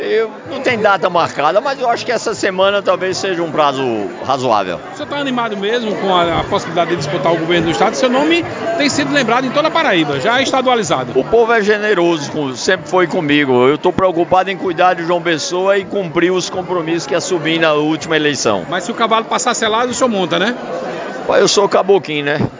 Durante entrevista à Arapuan FM nesta segunda-feira (17), questionado sobre a disputa, ele não descartou entrar nas discussões.